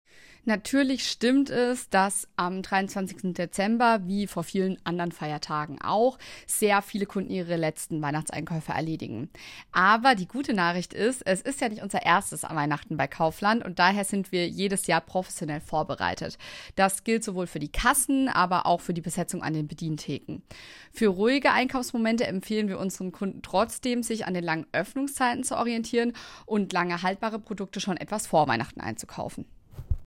O-Ton Einkaufen am 23. Dezember